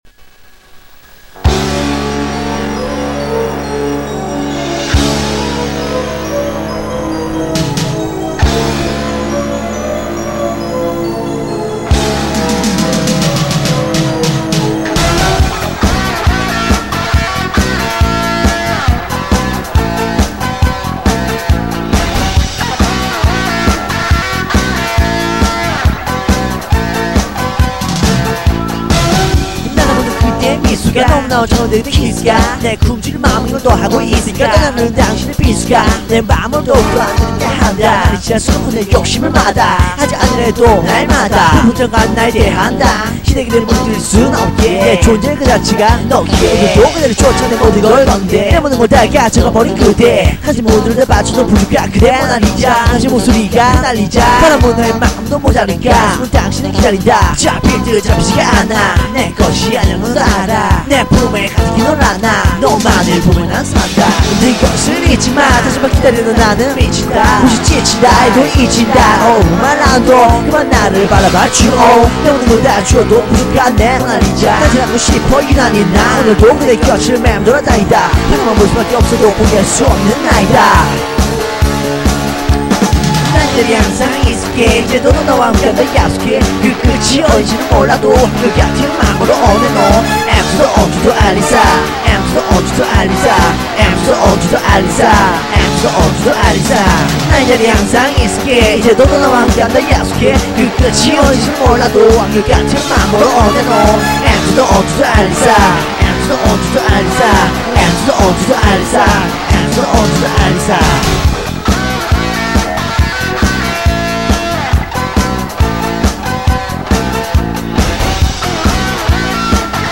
한 6년전? 군대 가기전 집에서 연습용으로 녹음했던 곡인데 이렇게 올려보네요